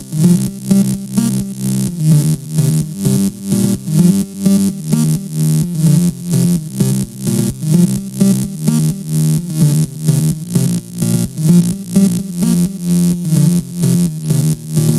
电音或大房间房屋超级齿形合成器 128 BPM
Tag: 128 bpm Electro Loops Synth Loops 2.52 MB wav Key : Unknown